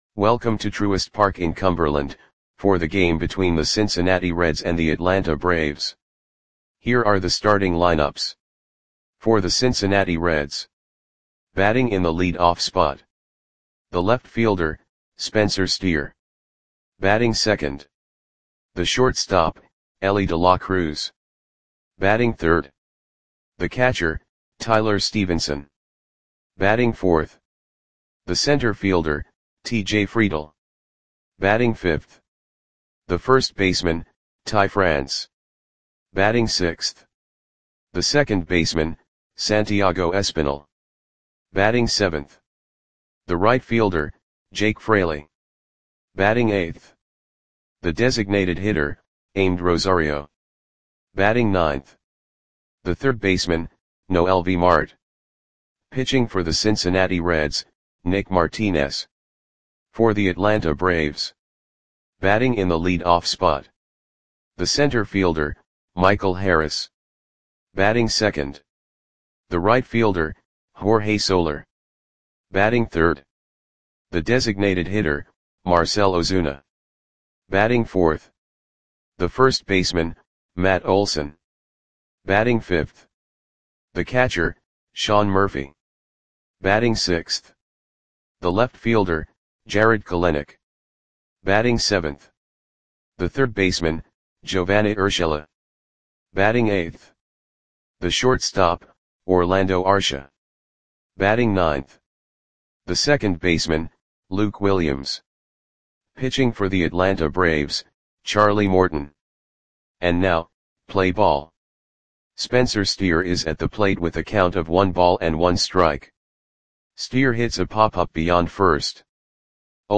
Audio Play-by-Play for Atlanta Braves on September 9, 2024
Click the button below to listen to the audio play-by-play.